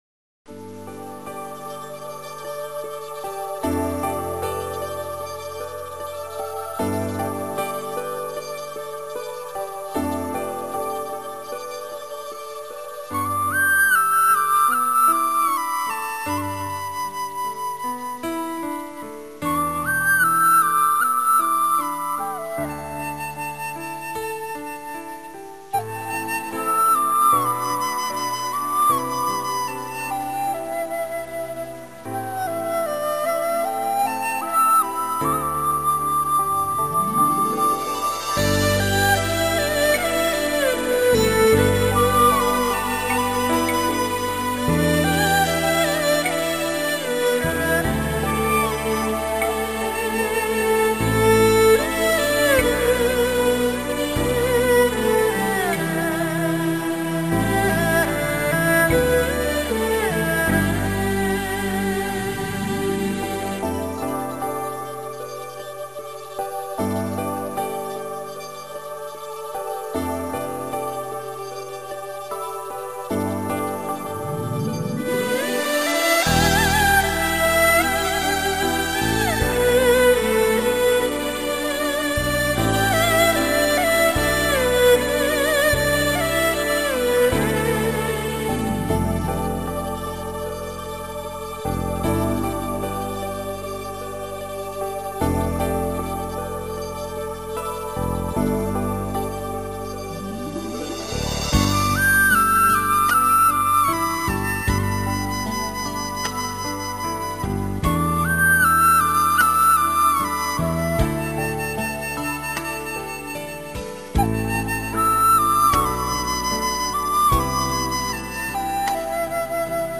音画欣赏：笛．江南烟柳